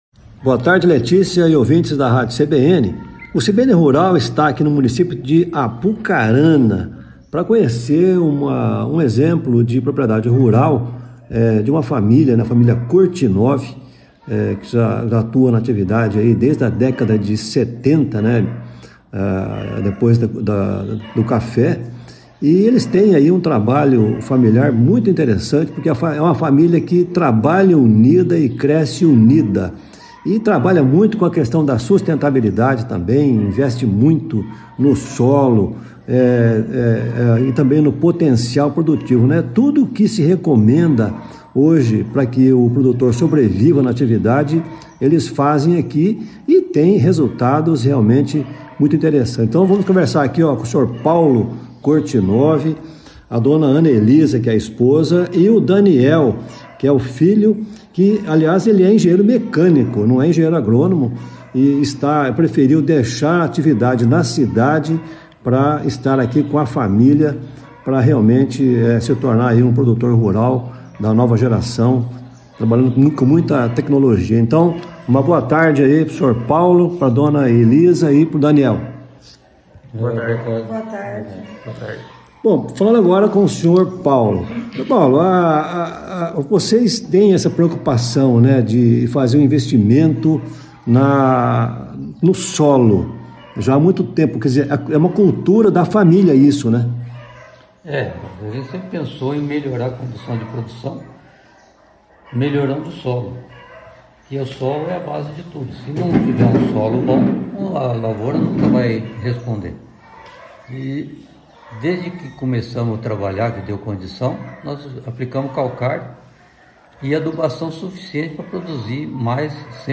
O CBN Rural esteve na propriedade, acompanhando o Rally Cocamar de Produtividade.